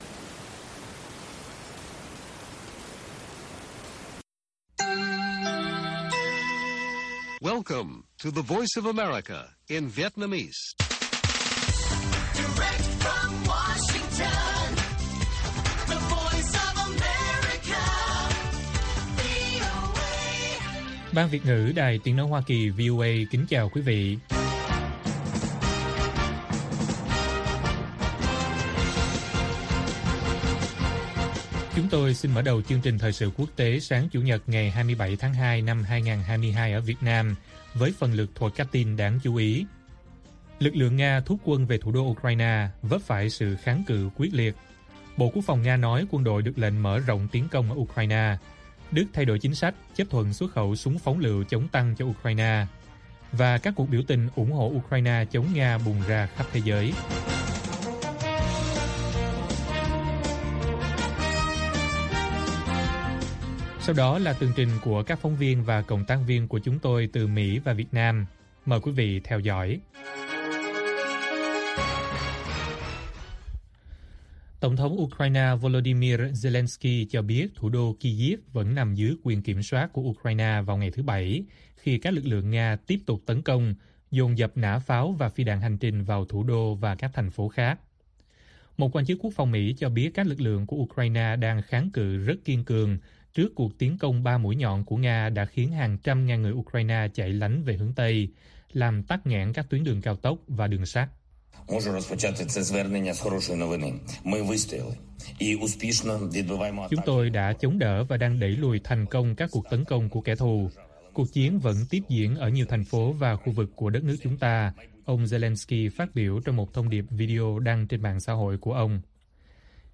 Lực lượng Nga ấp phải ‘kháng cự quyết liệt’ khi tiến vào thủ đô Ukraine | Bản tin VOA